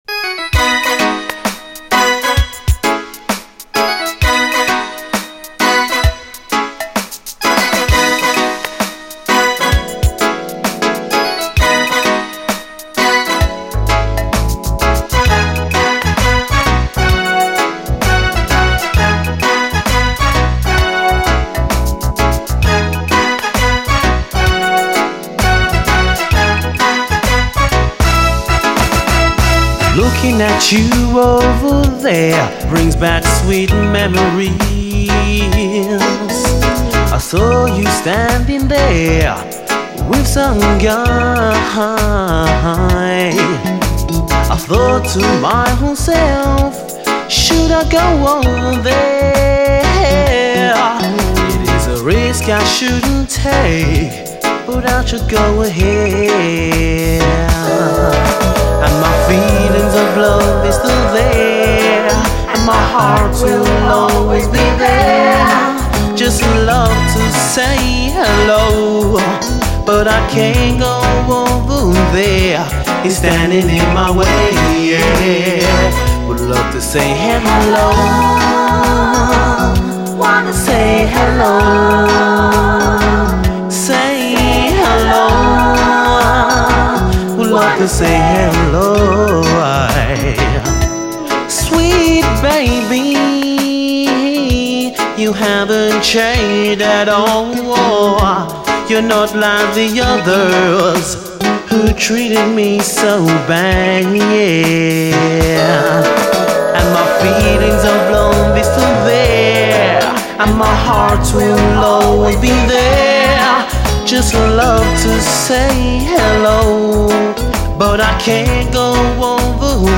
REGGAE
• COUNTRY : UK
清涼感と透明感あるクリアなシンセ・サウンド＆女性コーラスが美しく
何より、優しくてあったかいメロディー、曲自体が非常にイイです。後半のダブもビューティフル。